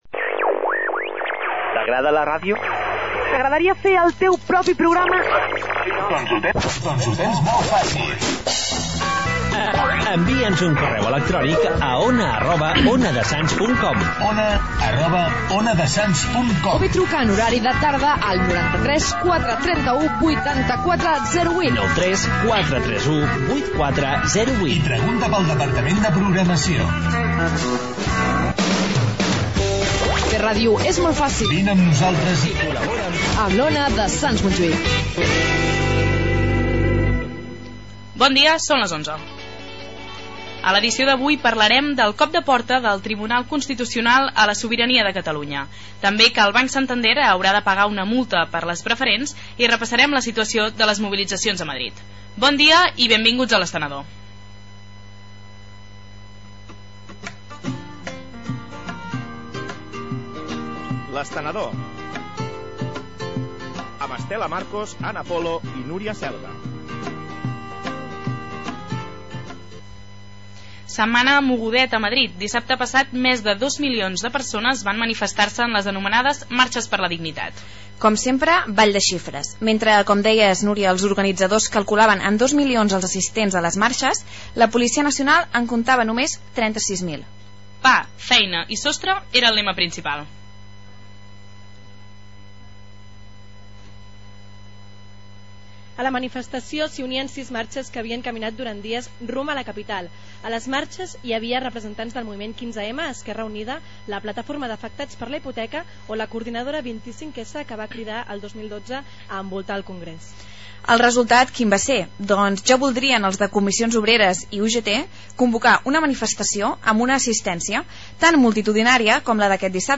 Gènere radiofònic Informatiu
Banda FM